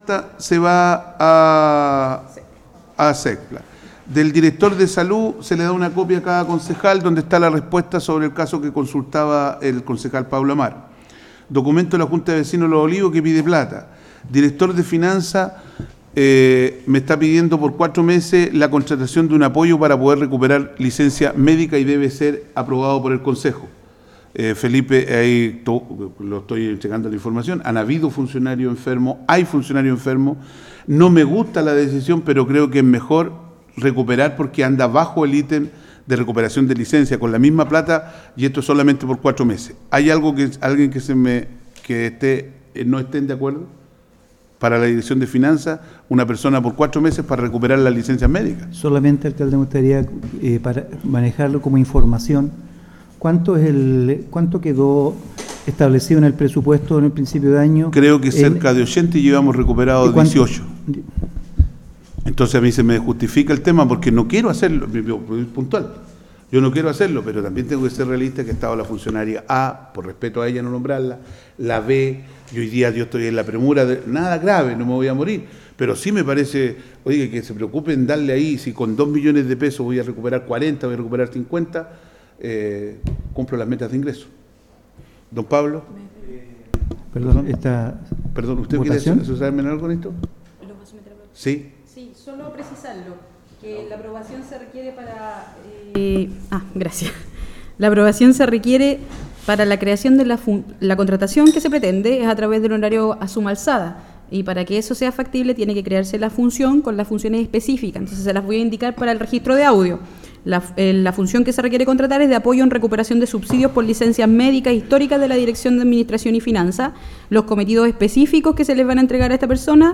Audios del Concejo